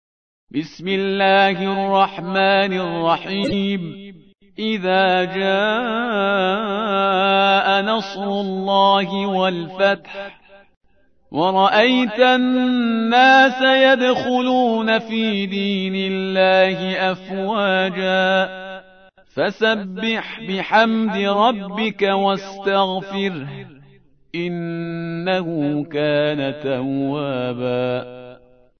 تحميل : 110. سورة النصر / القارئ شهريار برهيزكار / القرآن الكريم / موقع يا حسين